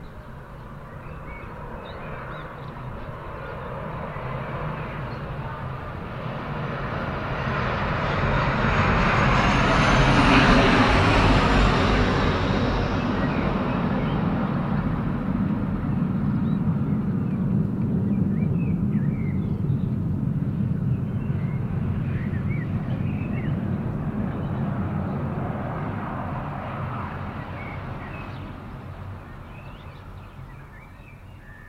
Turbulence_sounds.mp3